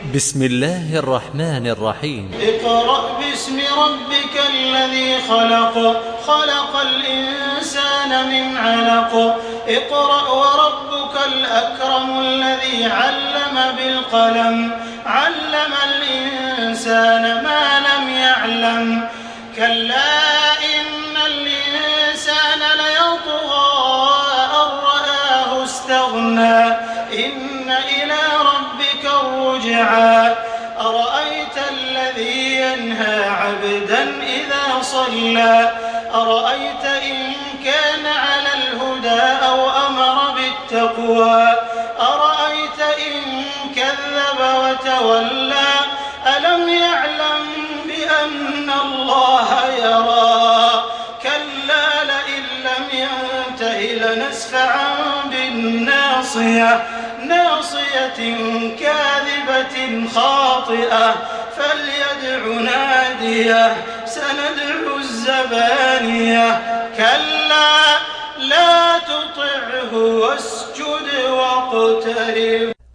Surah আল-‘আলাক্ব MP3 in the Voice of Makkah Taraweeh 1428 in Hafs Narration
Murattal